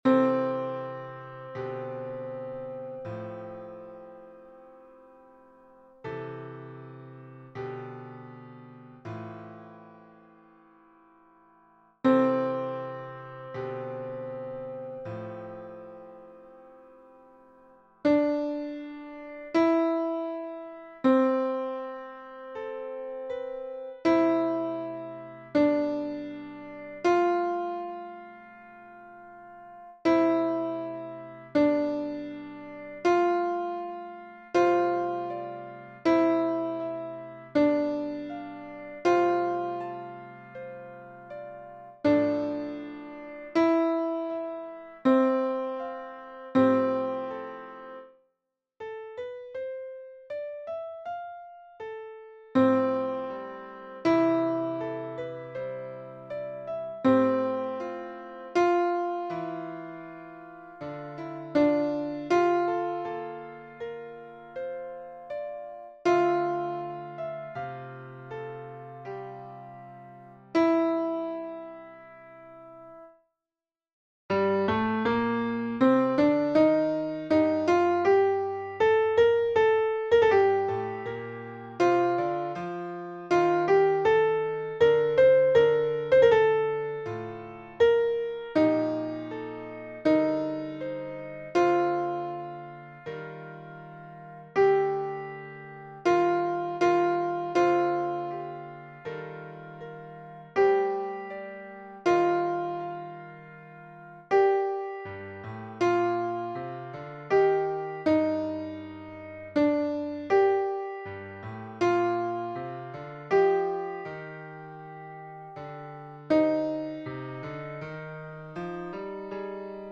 - Pièce pour chœur à 4 voix mixtes (SATB) + piano